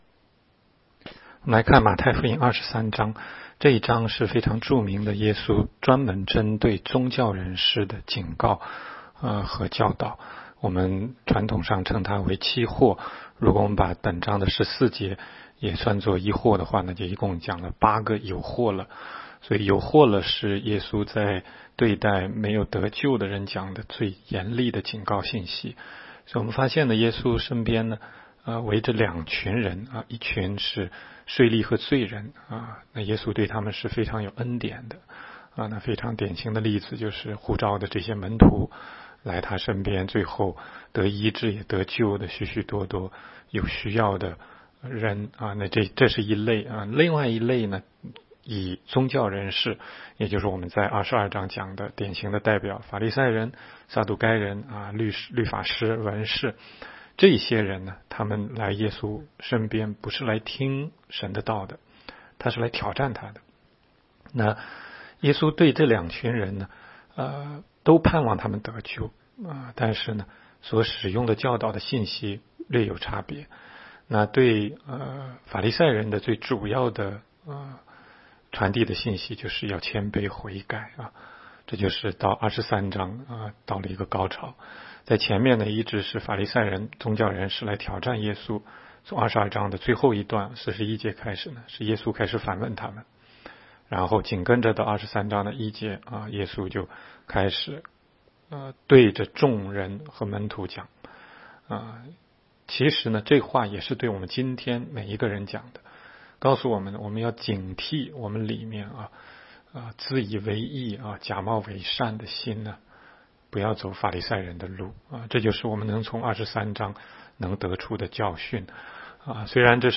16街讲道录音 - 每日读经-《马太福音》23章